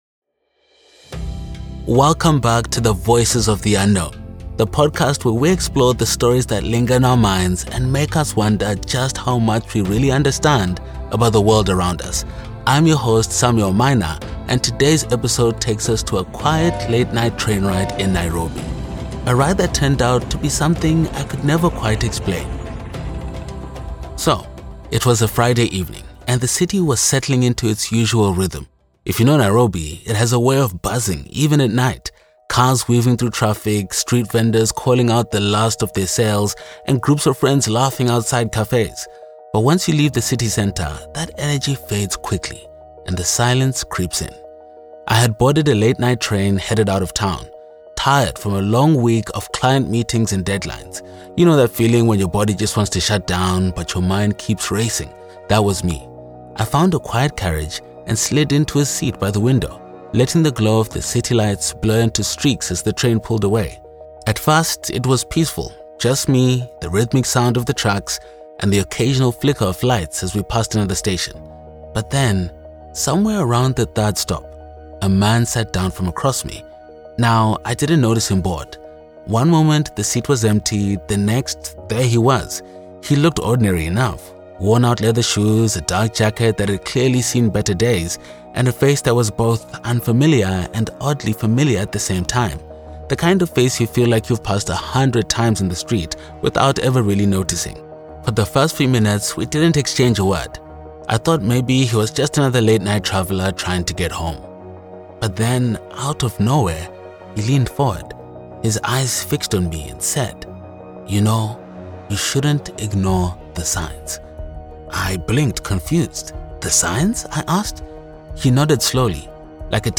Podcast Voice Overs
English (South African)
Adult (30-50)